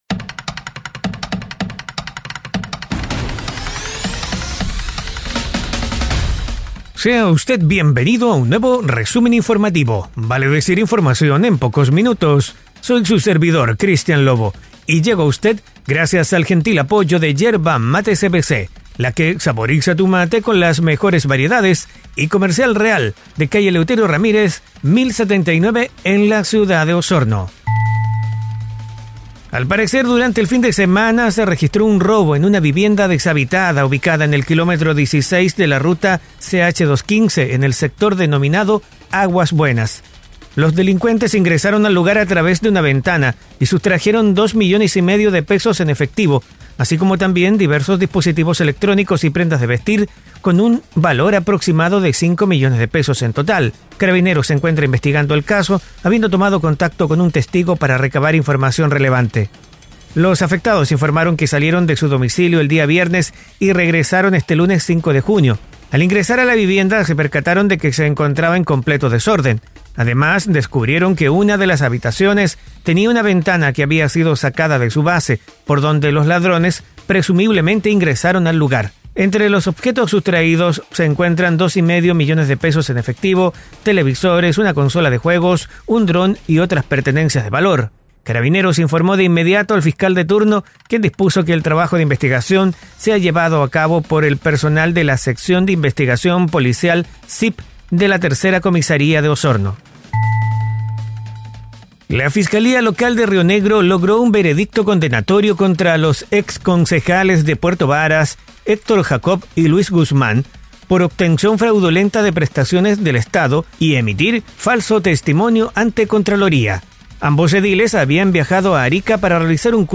🐺Resumen informativo es un audio podcast con una decena de informaciones en pocos minutos, enfocadas en la Región de Los Lagos